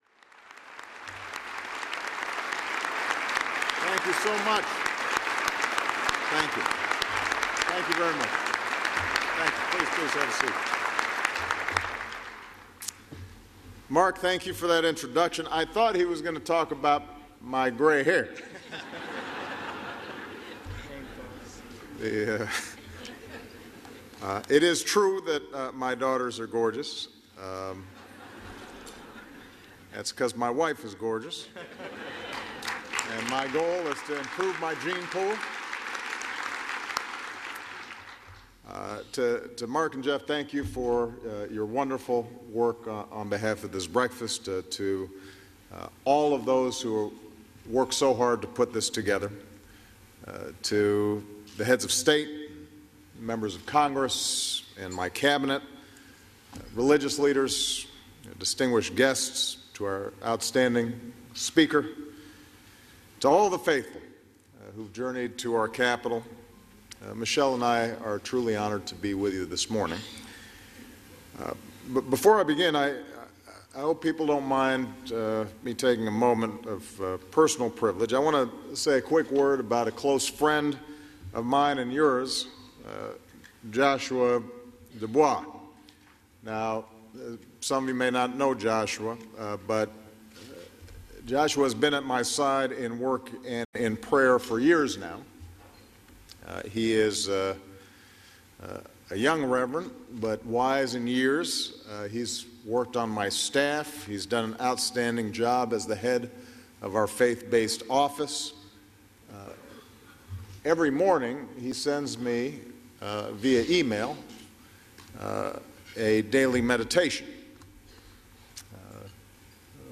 U.S. President Barack Obama speaks at the annual National Prayer Breakfast in Washington D.C